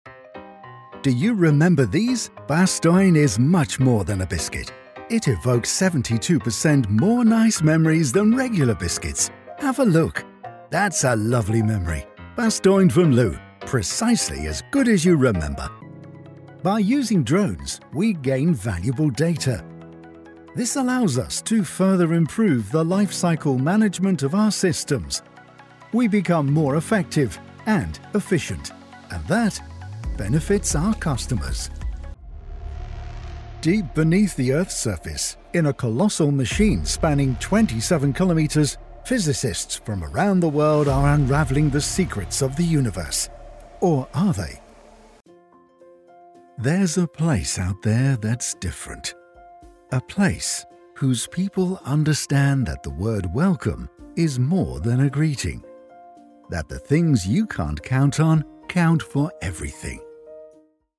English (British)
Versatile, Reliable, Corporate, Mature, Natural
Commercial